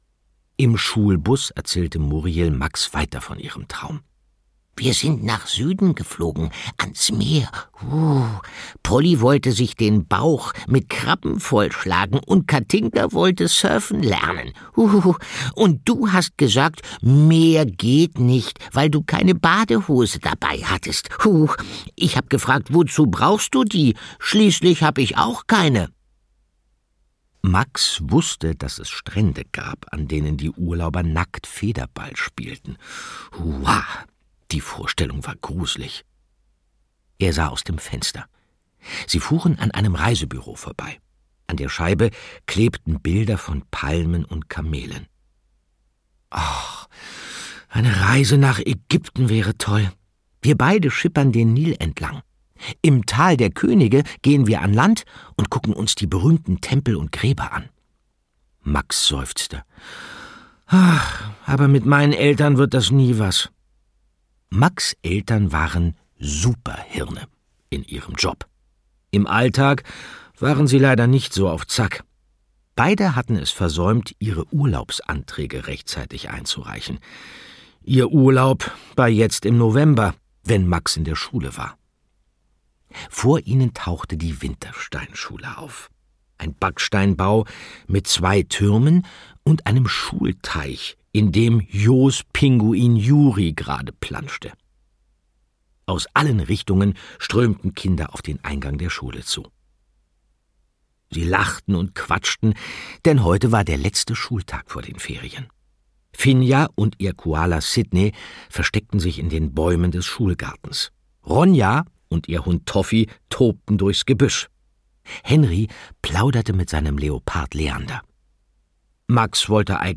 Die Schule der magischen Tiere - Endlich Ferien 7: Max und Muriel - Margit Auer - Hörbuch